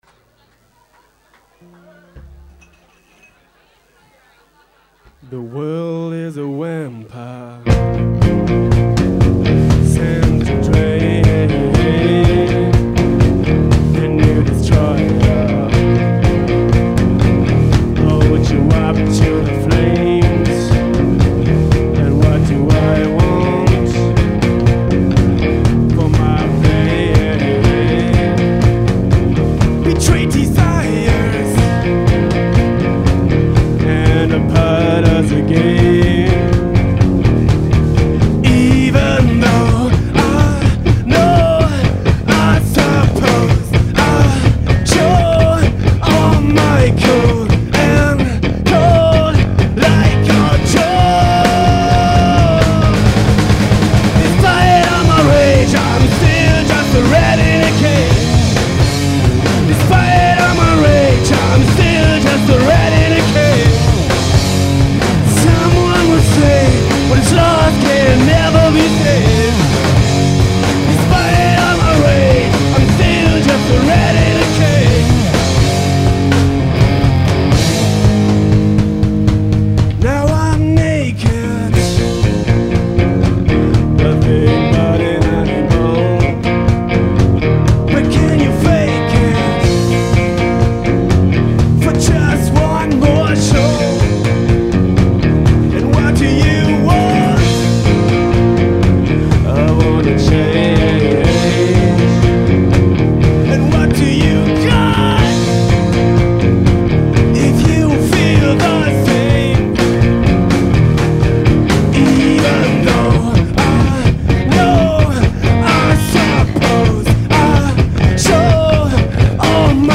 Cover-Songs (live):